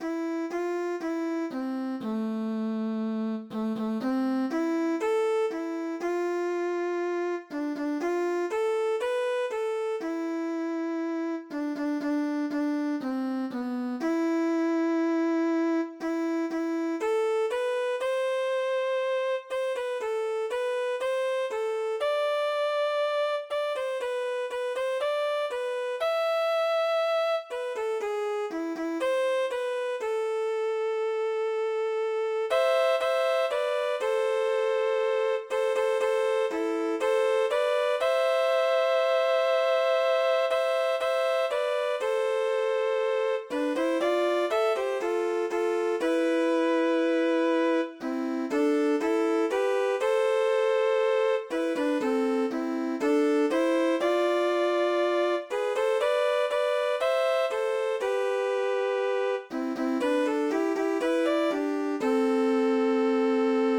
Thánh Ca Phụng Vụ